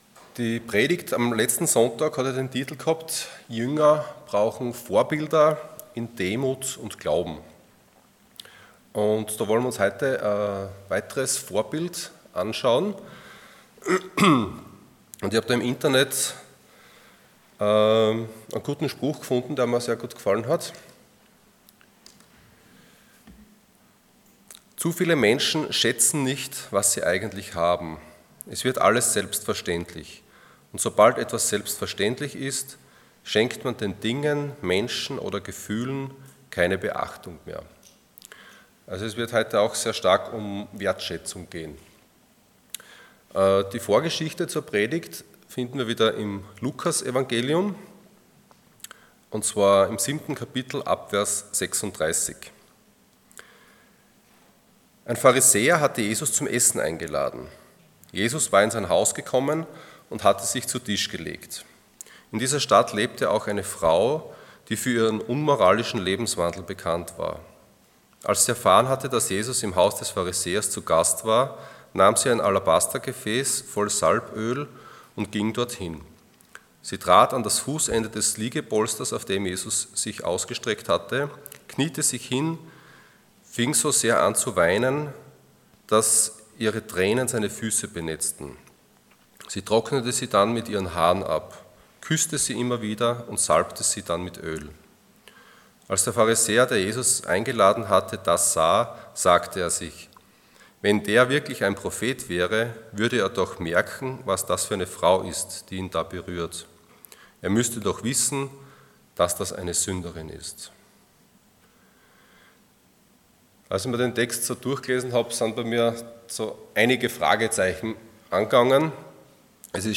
Passage: Luke 7:36-50 Dienstart: Sonntag Morgen